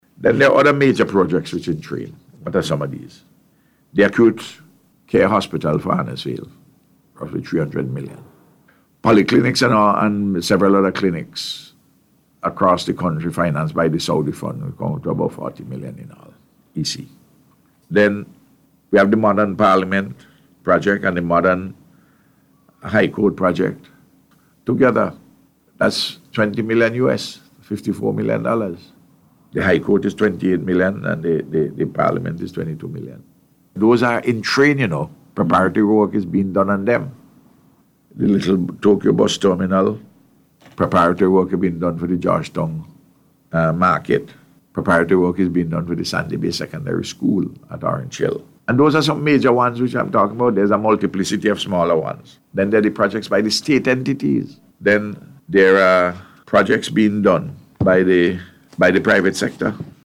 The Prime Minister provided an update on infrastructural projects taking place across the island on NBC Radio on Wednesday.